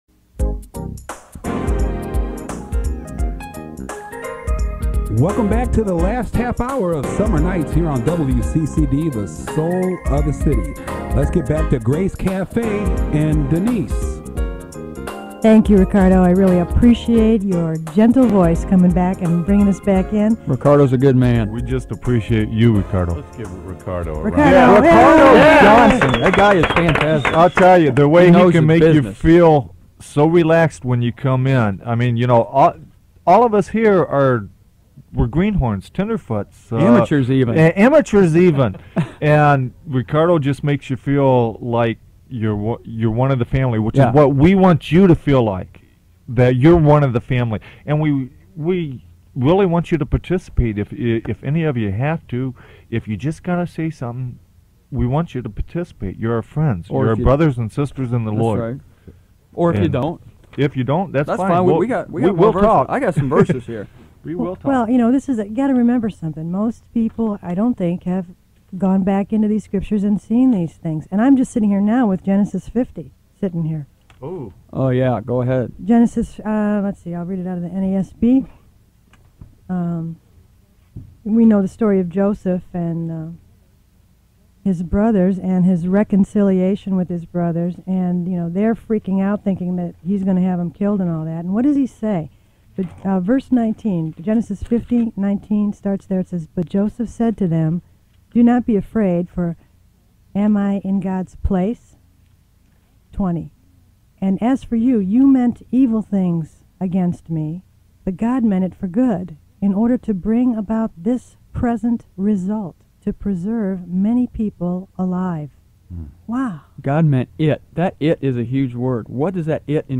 Five friends and myself commandeered the Christian radio waves of WCCD, Cleveland, in the summer of 1999.
We blew up all their pet doctrines, while drinking soda on the air and being jocular.
And though we challenged local pastors to call us (it was live, call-in radio) and tell us where we were wrong, none of them did.